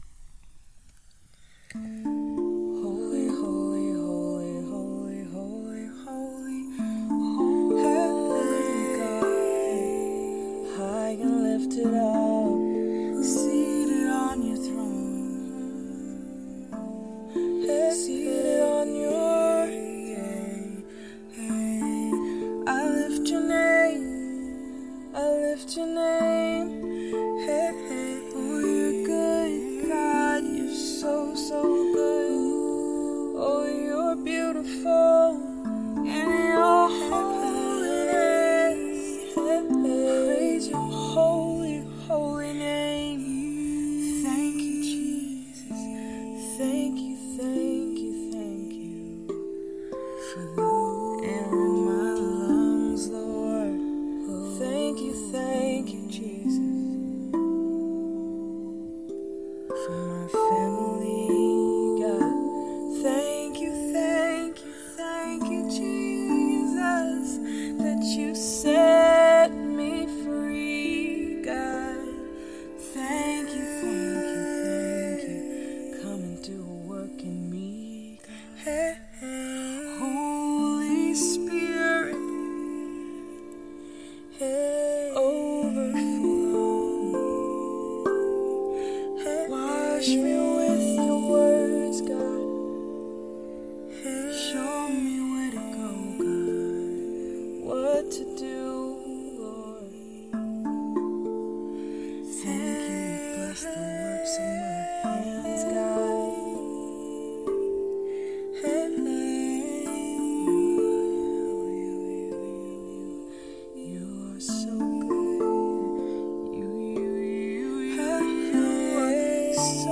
Spontaneous: How Do I Do The Works Of God Rav Vast Sessions 11-14-24